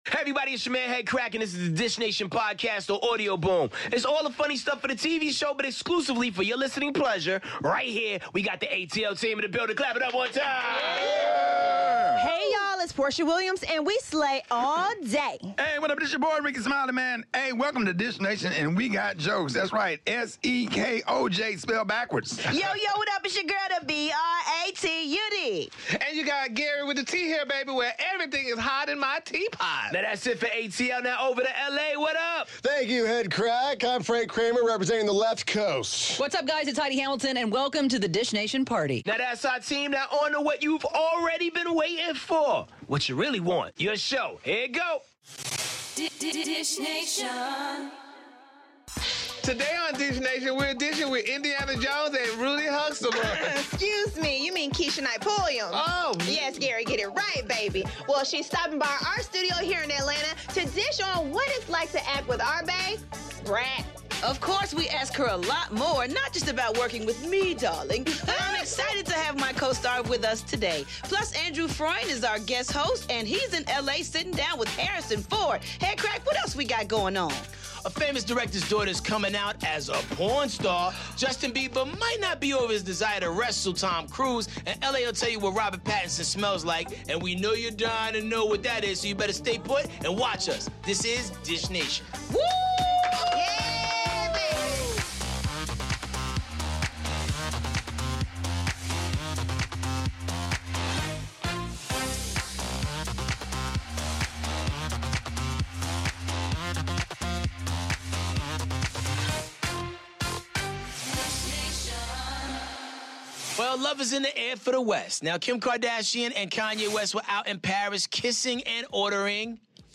Keshia Knight Pulliam is in studio so watch today’s Dish Nation for some celebri-tea!